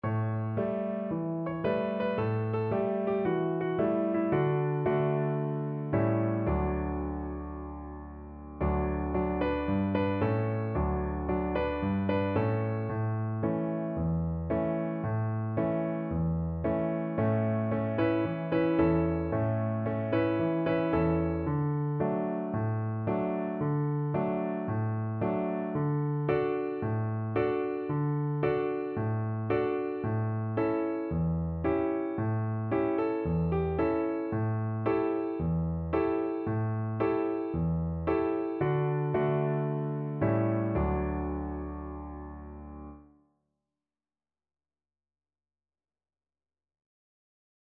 4/4 (View more 4/4 Music)
Cheerfully! =c.112
Traditional (View more Traditional Voice Music)